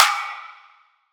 • Treble-Heavy Trap Acoustic Snare D Key 11.wav
Royality free acoustic snare sample tuned to the D note.
treble-heavy-trap-acoustic-snare-d-key-11-QO0.wav